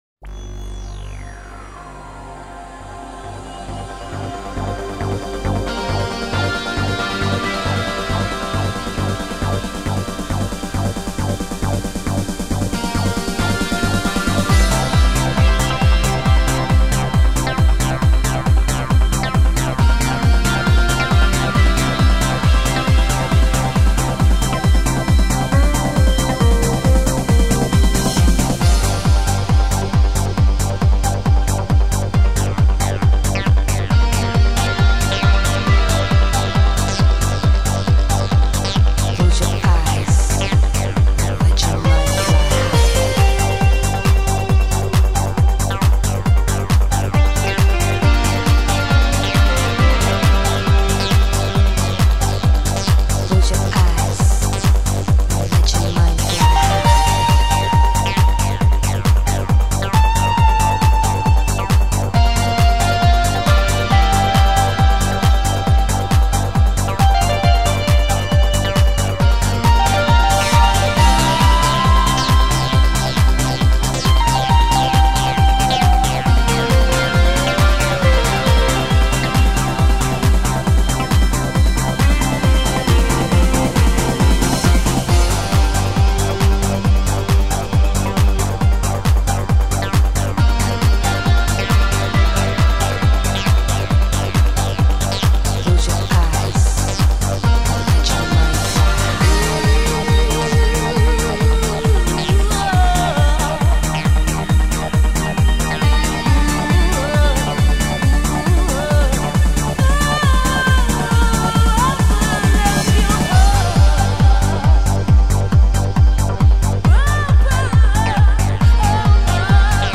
Энергия - эмоции - чувства - движение...
Жанр:Electronic